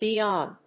アクセントbe ón (for…)